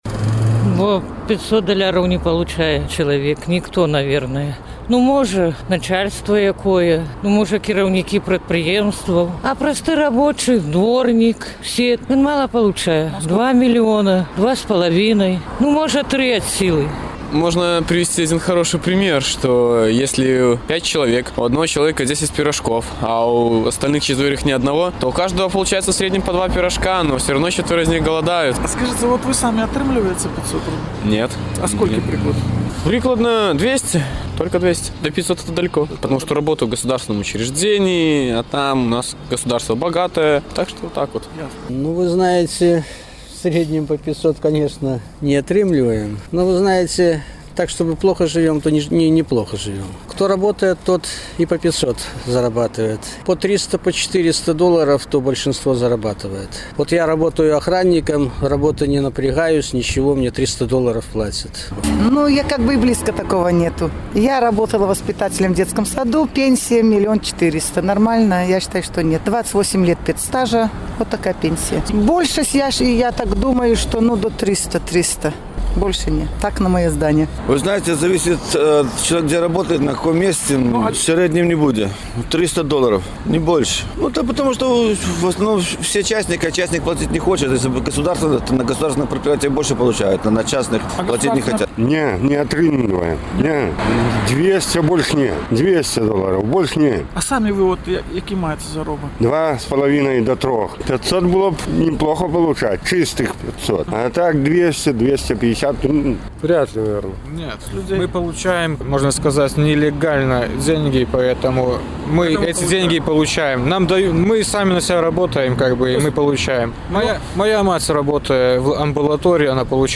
Адказваюць гарадзенцы